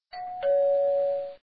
Doorbell Sound - doorbell sound effect
DOOR BELL SOUND EFFECT: A typical home door bell. A small yet simple tone downloaded in less than 1 second.
doorbell.mp3